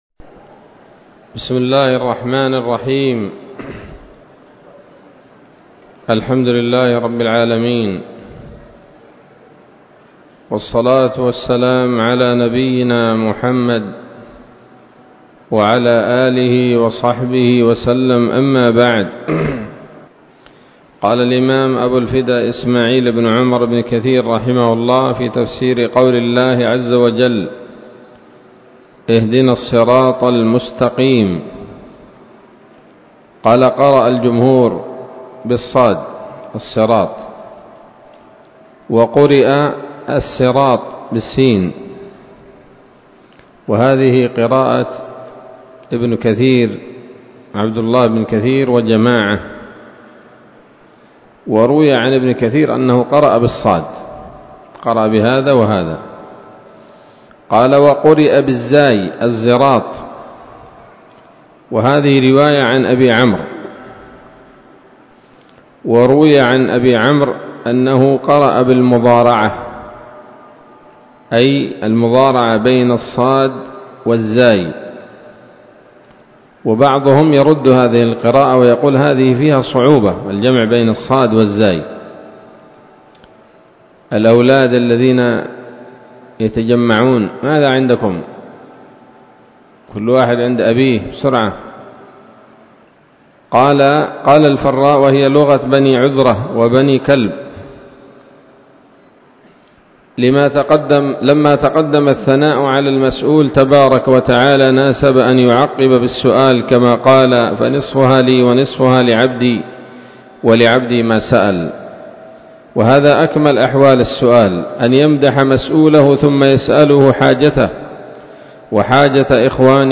الدرس الثامن عشر من سورة الفاتحة من تفسير ابن كثير رحمه الله تعالى